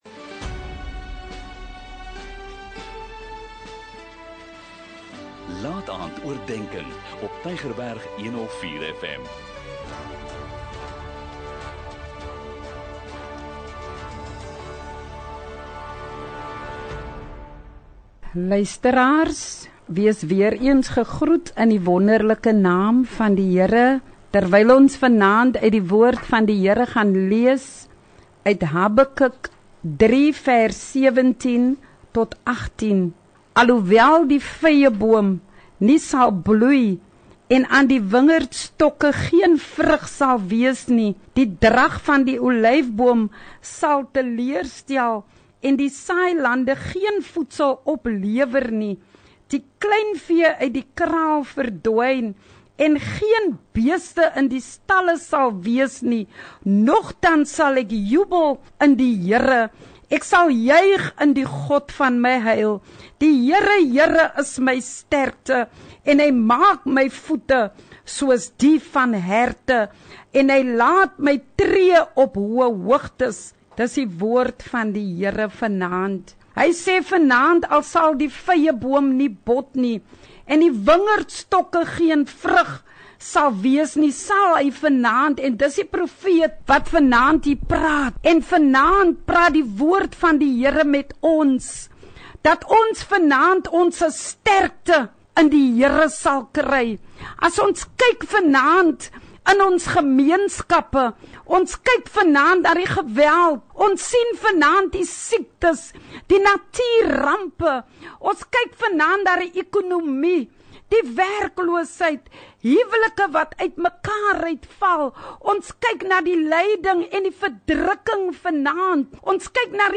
n Kort bemoedigende boodskap, elke Sondagaand om 20:45, aangebied deur verskeie predikers